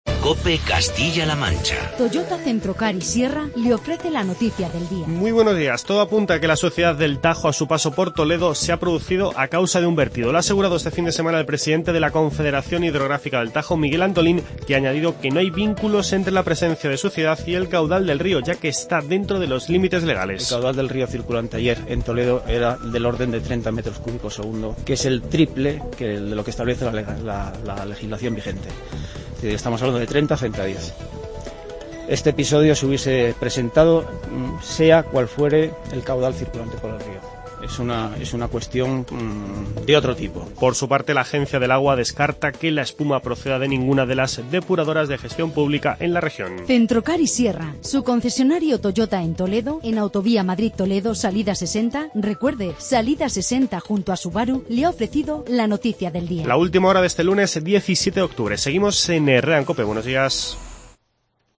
Informativo COPE Castilla-La Manca
AUDIO: Destacamos las palabras de Miguel Antolín, presidente de la Confederación Hidrográfica del Tajo.